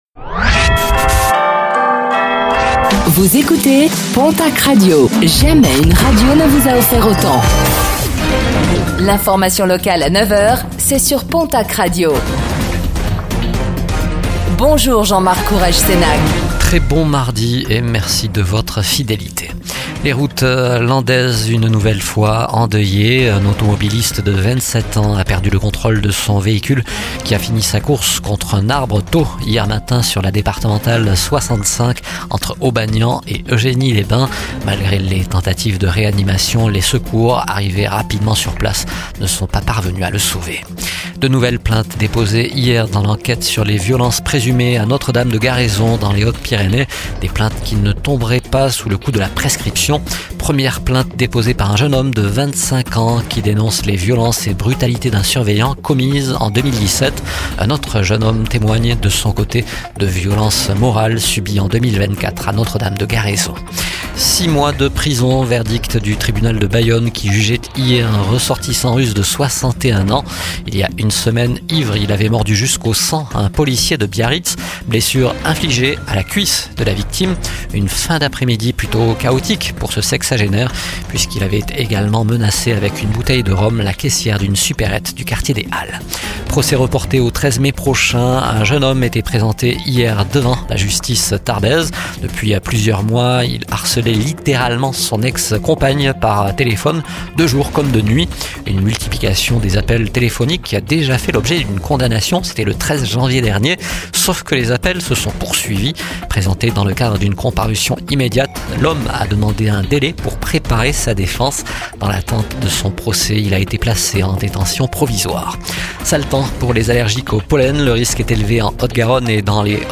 Infos | Mardi 08 avril 2025